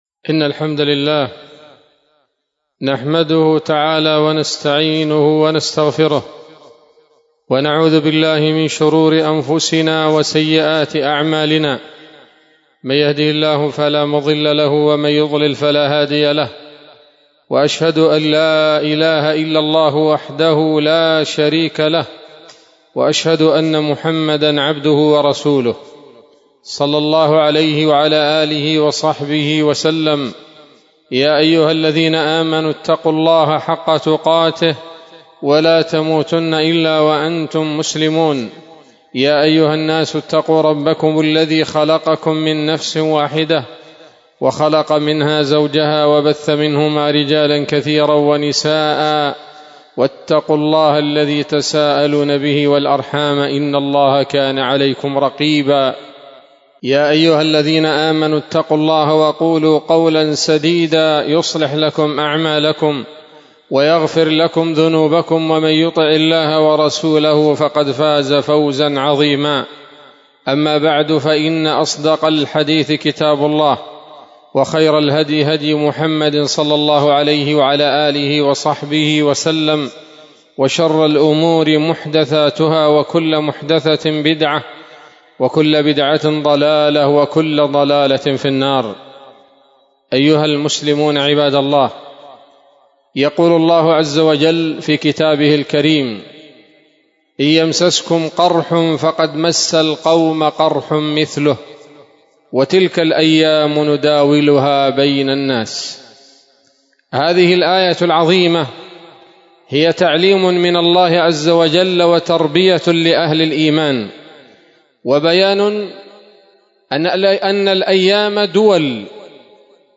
خطبة جمعة بعنوان: (( تعوذوا من شماتة الأعداء )) 2 جمادى الأولى 1447 هـ، دار الحديث السلفية بصلاح الدين